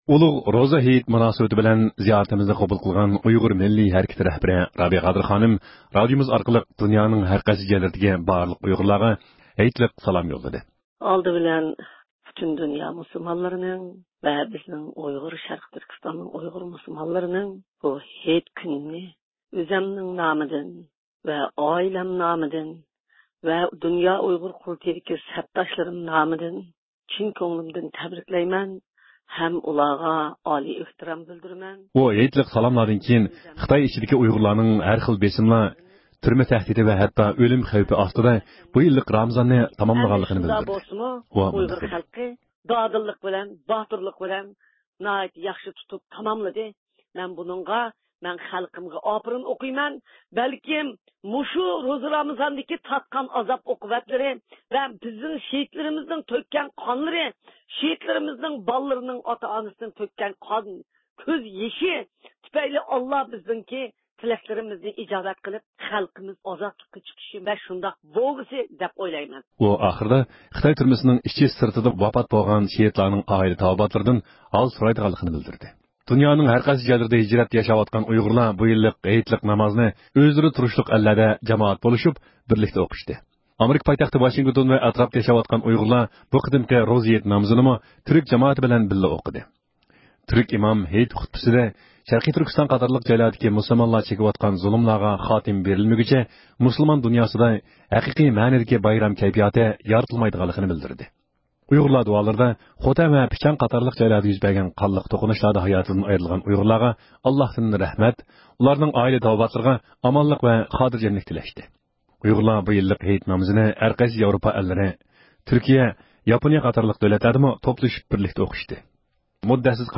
erkin-asiya-radiosi.jpgئەركىن ئاسىيا رادىئوسى ئۇيغۇر بۆلۈمى ھەپتىلىك خەۋەرلىرى